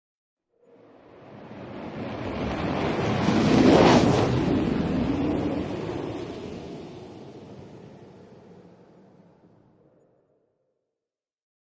Download Fly sound effect for free.
Fly